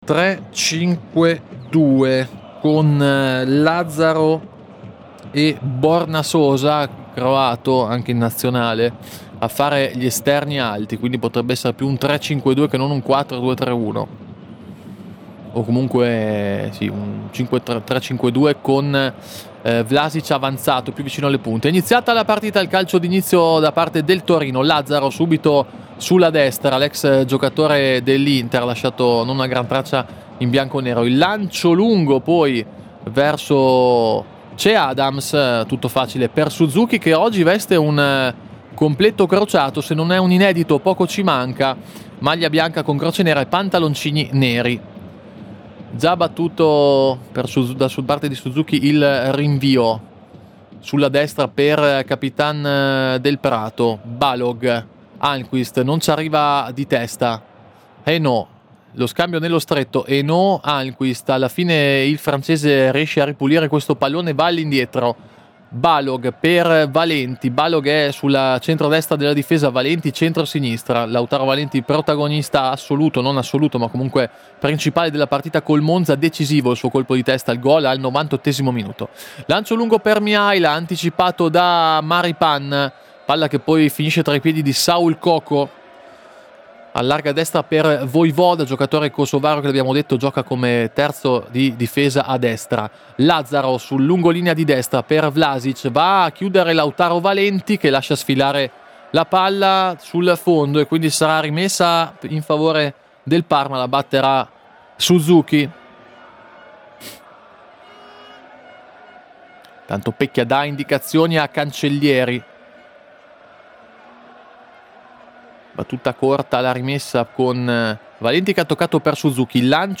Si gioca a Torino allo Stadio Grande Torino.